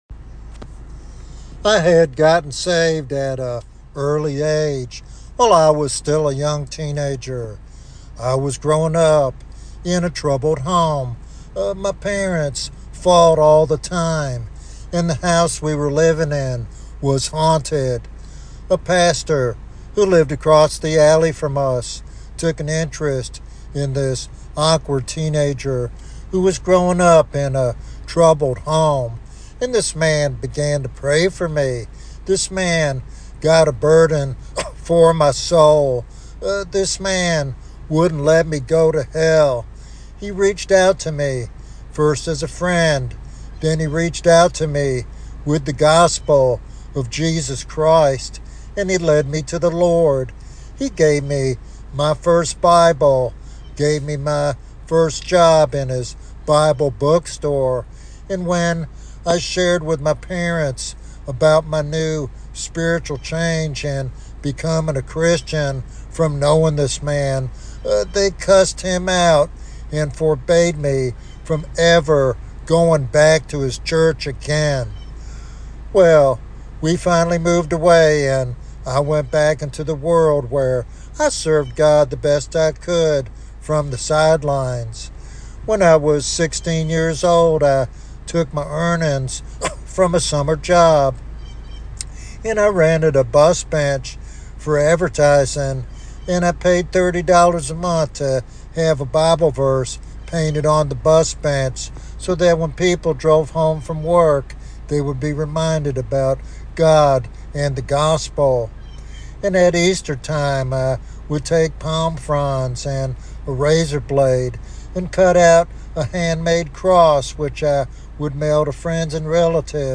This sermon encourages believers to evaluate their own commitment and to live a life that truly counts for eternity.